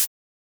edm-hihat-02.wav